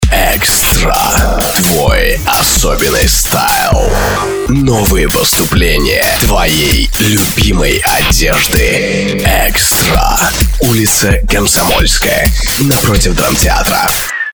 стильный ролик. Целевая аудитория - молодежь.